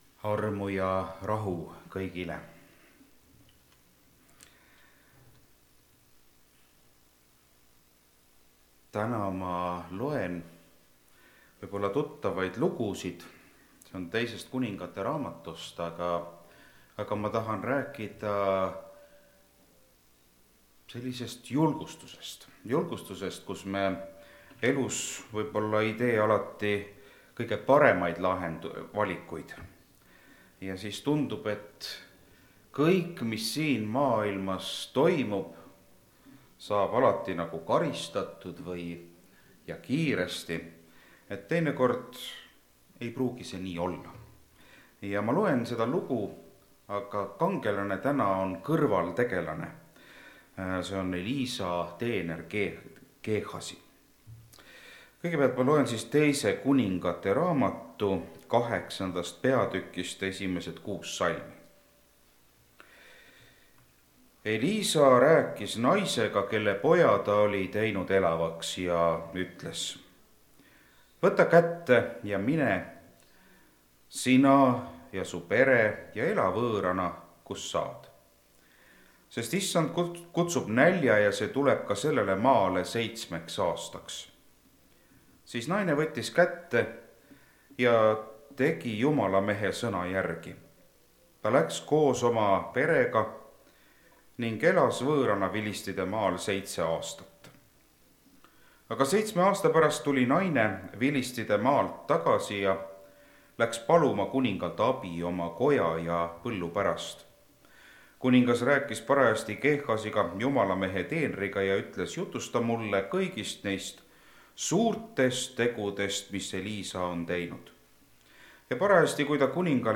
Rakveres